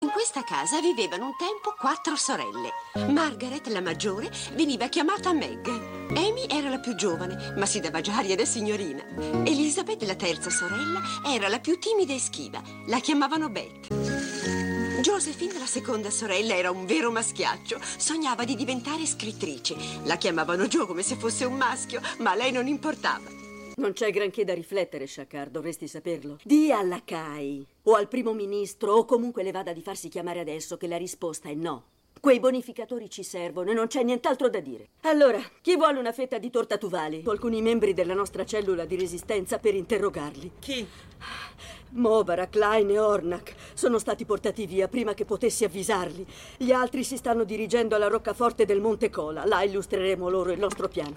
"Piccole donne", in cui è la voce narrante, e nel telefilm "Star Trek: Deep Space Nine"